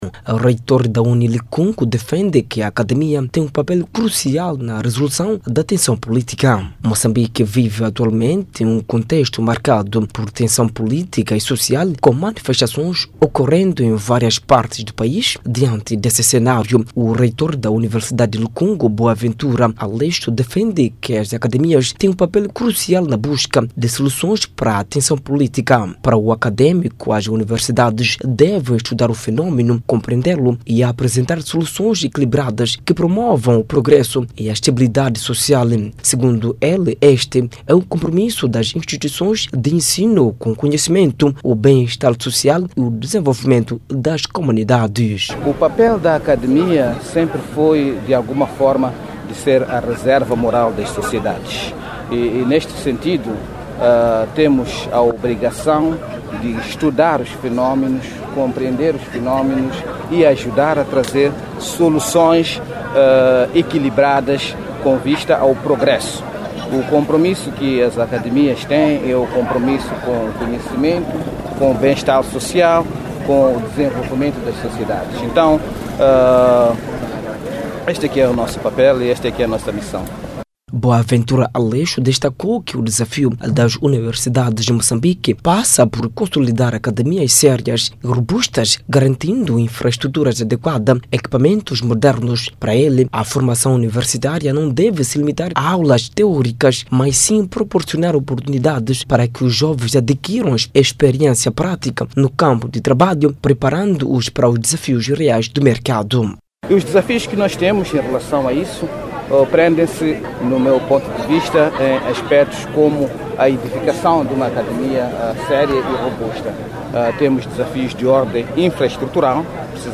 fez essas declarações durante uma entrevista concedida aos órgãos de informação na cidade da Beira.